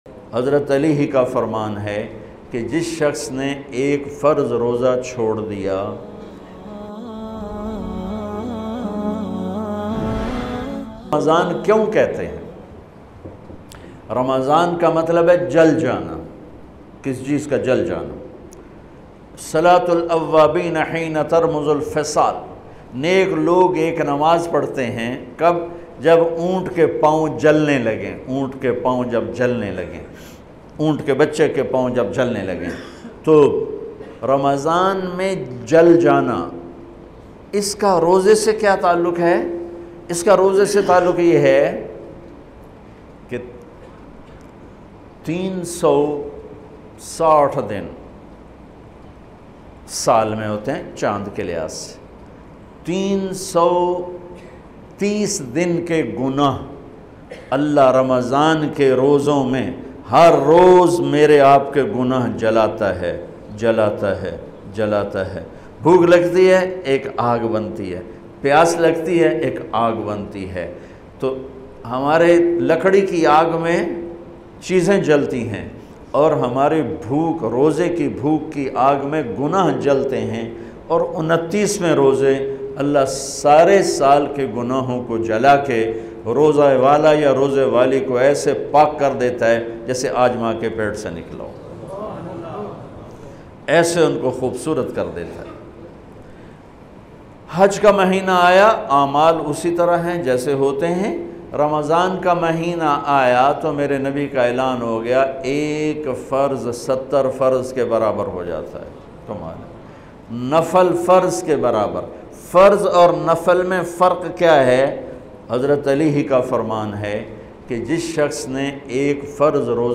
farz roza chorne ki saza Maulana Tariq Jameel latest bayan mp3 free download.